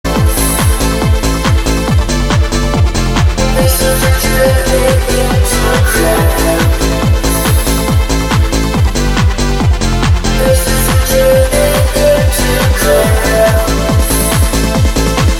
It is on the verge of being a "pop" techno type song.